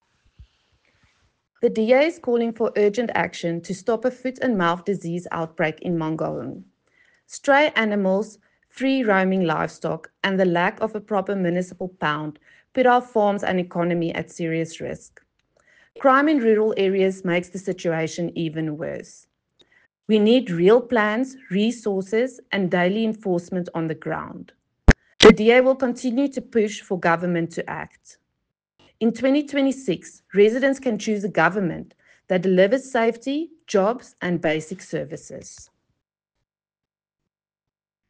English and Afrikaans soundbites by Cllr Maryke Davies and